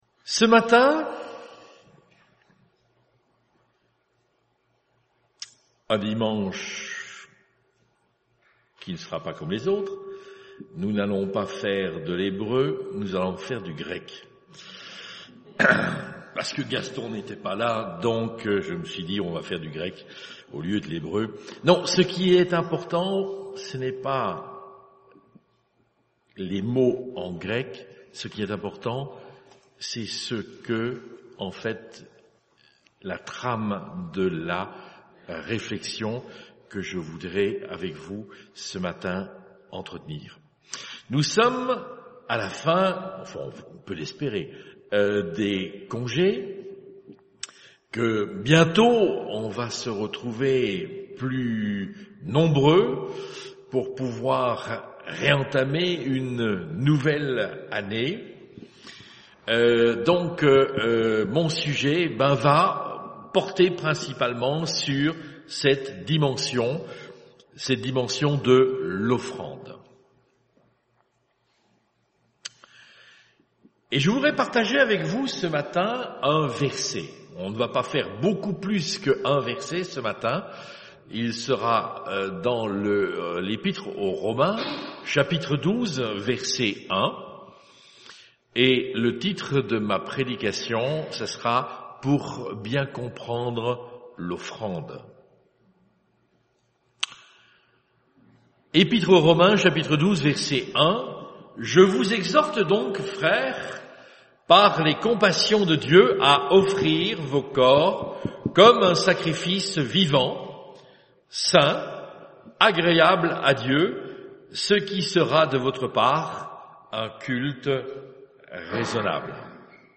1 Type De Service: Dimanche matin Bible Text